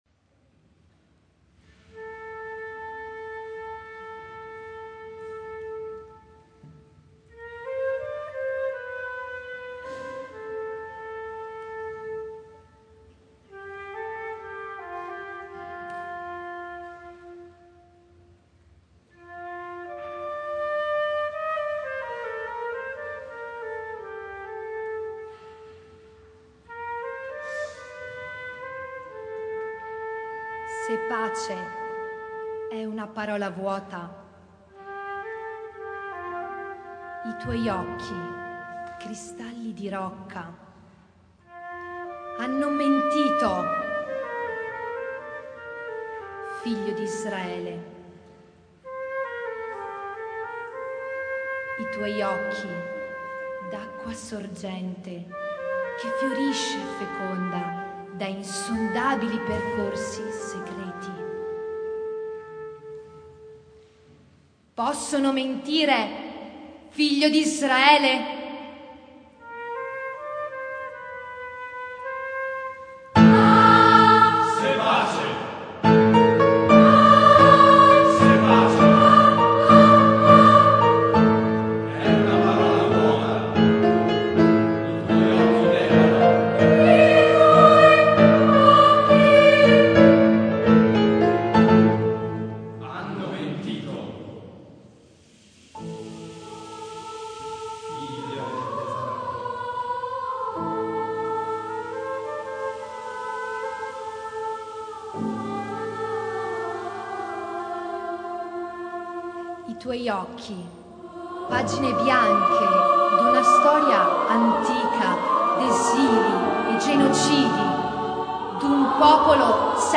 · Contenuto in Choir or Solo Voices + Instrumental ensemble
Secular cantata
Marostica – Poesia in Canto 2007